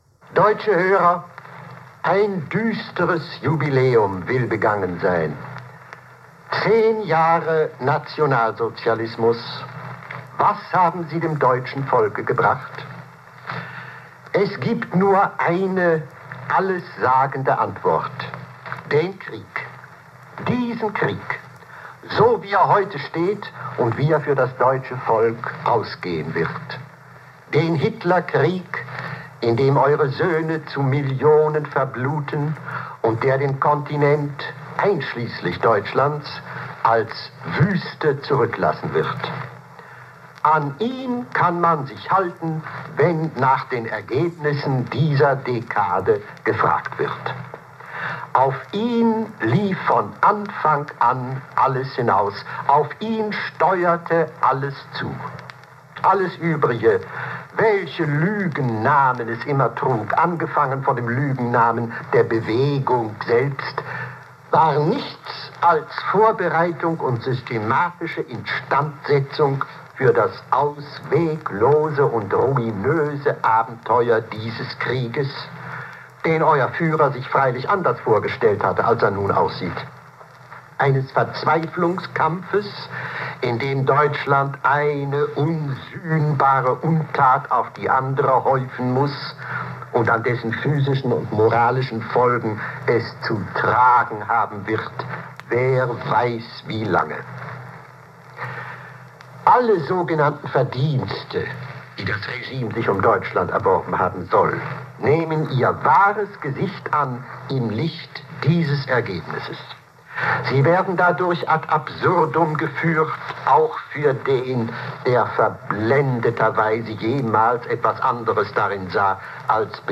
18. Jan. 1943, Ansprache an »Deutsche Hörer« zu zehn Jahren Nationalsozialismus
(Sprecher: Thomas Mann, 6:10 min)